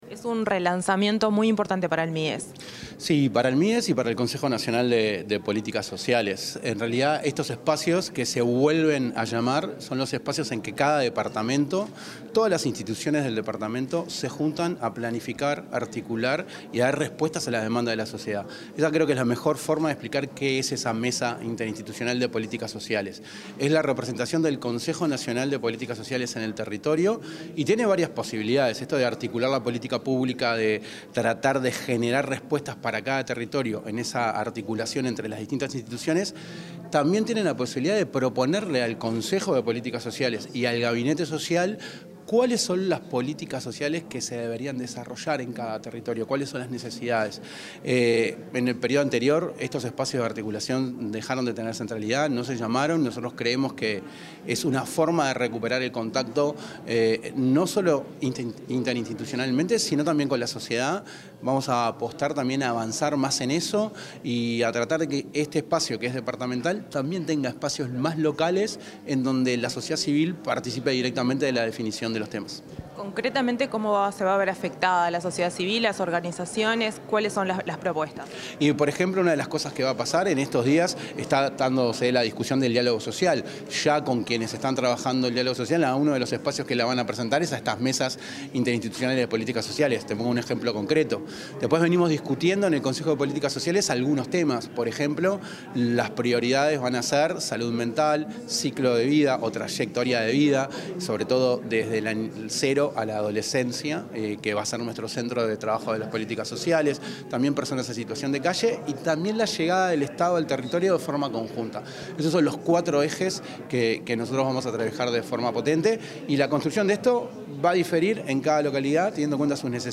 Declaraciones del subsecretario de Desarrollo Social, Federico Graña
El subsecretario de Desarrollo Social, Federico Graña, dialogó con la prensa tras participar en el relanzamiento de las Mesas Interinstitucionales de